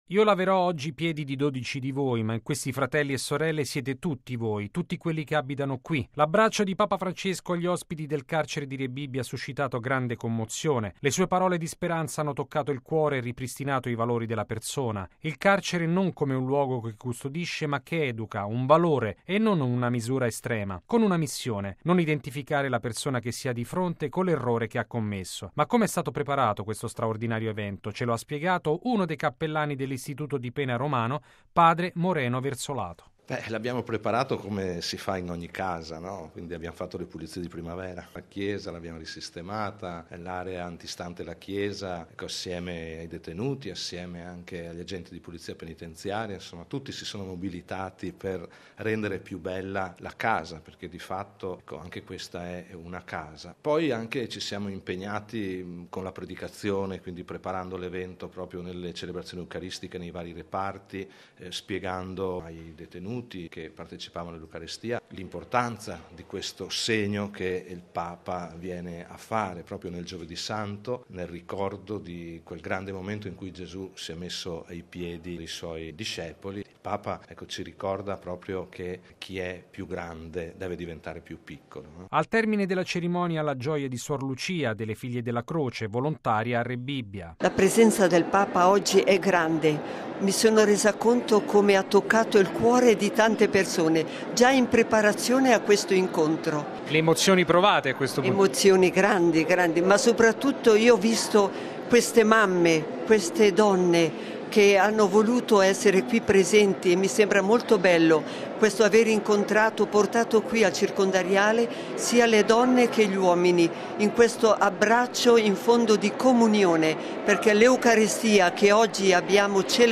Una selezione di detenuti ha scelto di indossare il camice, per prestare servizio durante la liturgia presieduta dal Santo Padre. La loro testimonianza e la loro commozione: